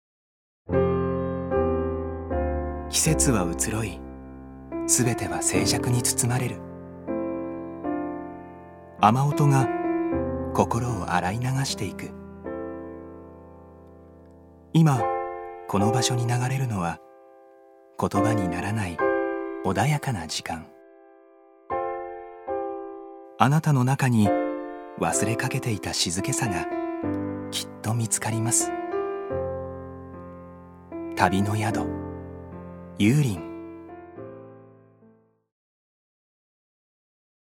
所属：男性タレント
ナレーション４